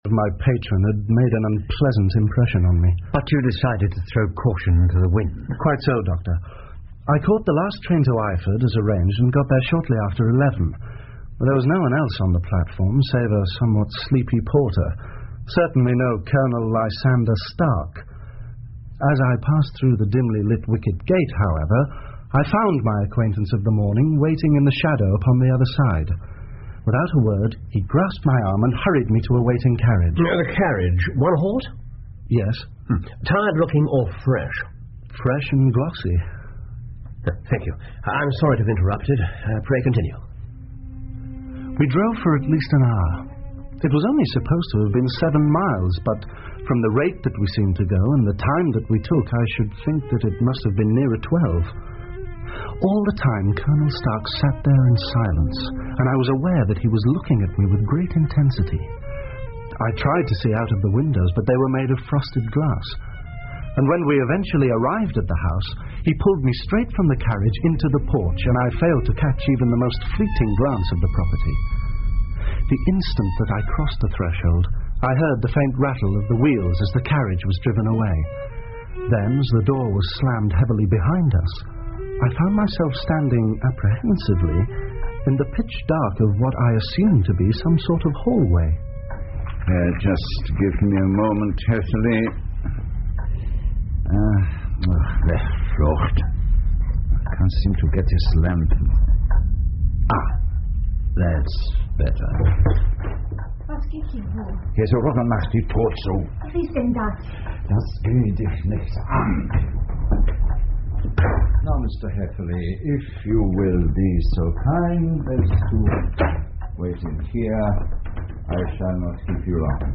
福尔摩斯广播剧 The Engineer's Thumb 4 听力文件下载—在线英语听力室